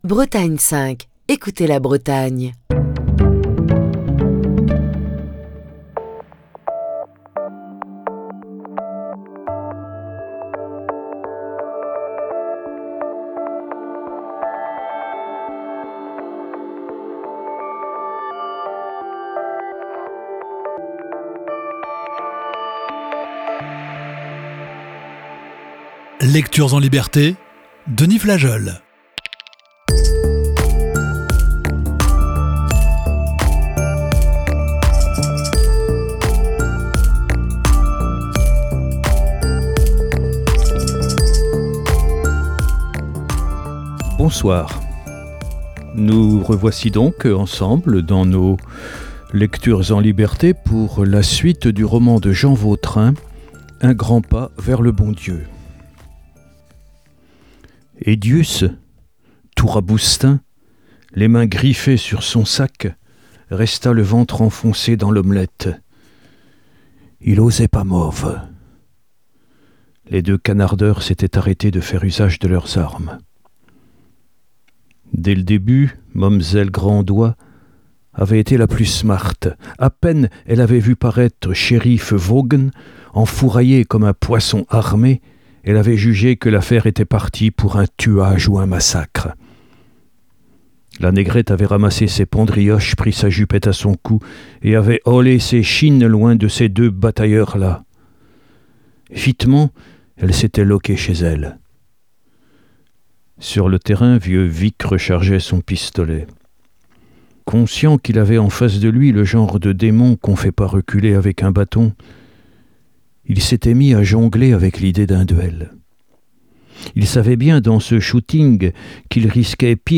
Voici ce soir la sixième partie de ce récit.